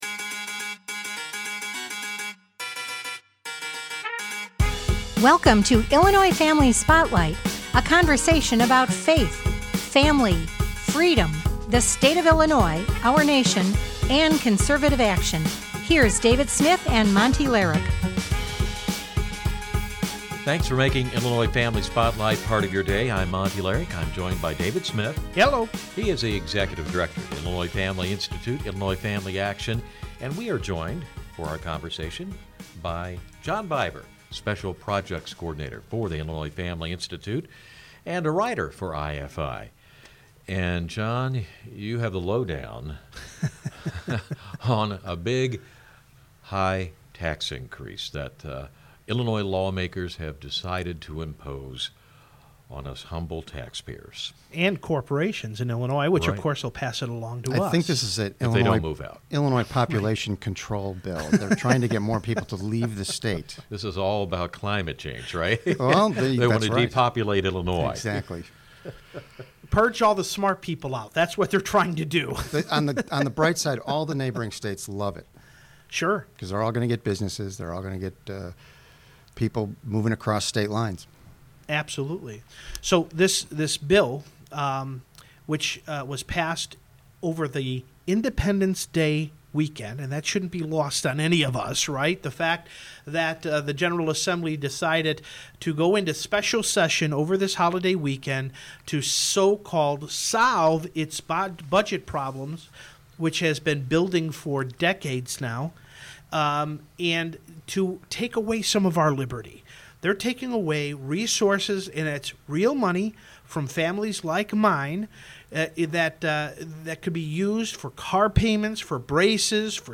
The second half of the show, due to technical difficulties, had to be redone